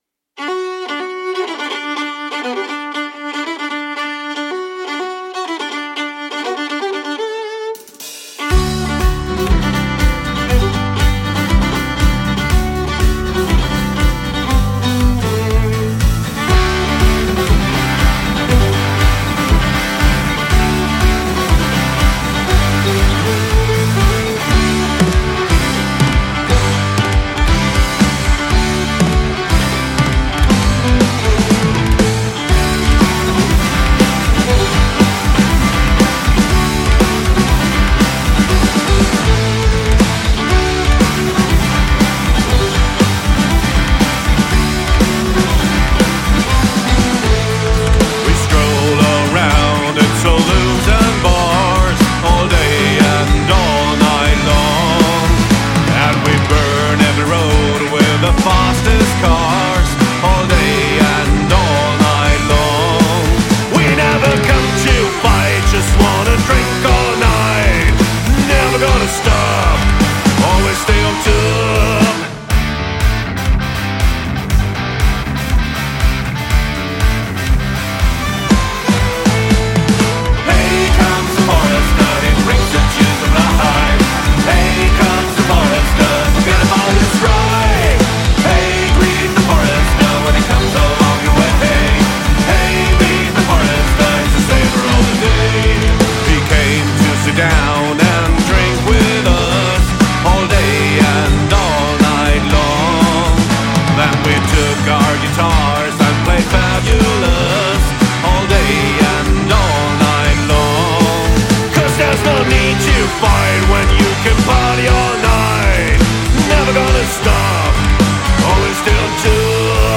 forest.mp3